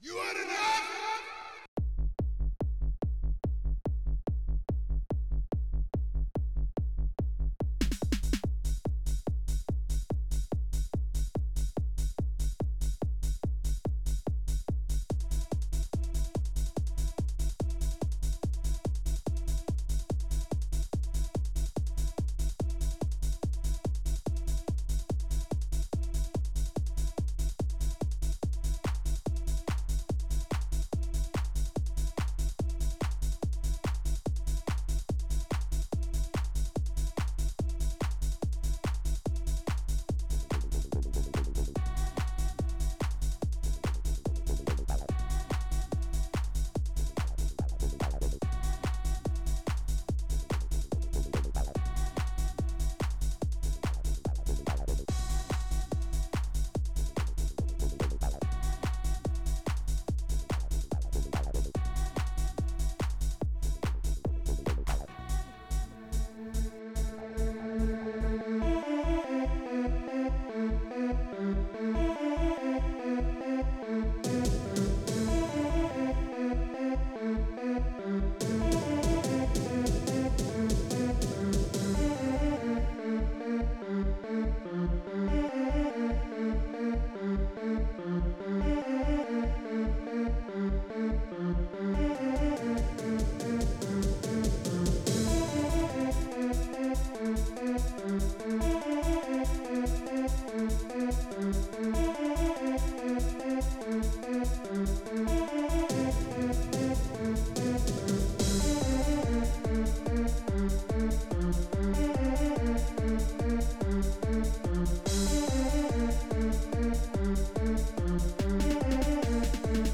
OctaMED Module